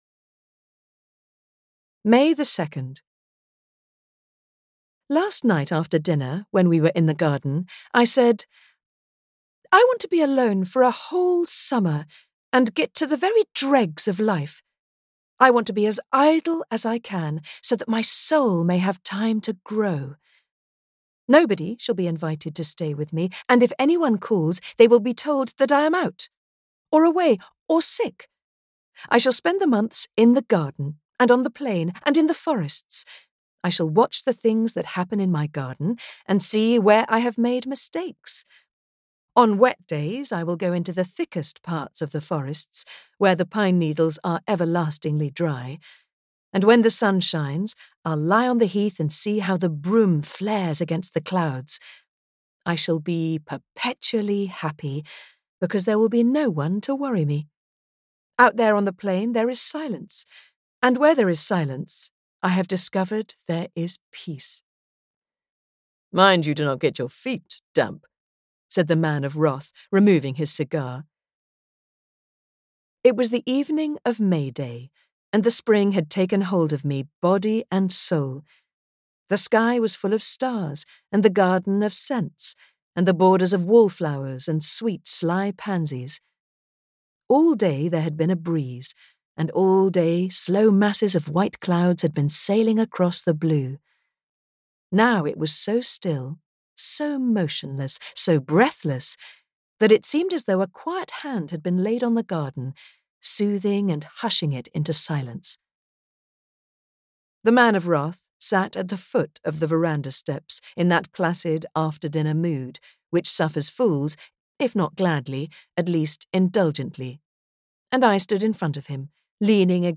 The Solitary Summer audiokniha
Ukázka z knihy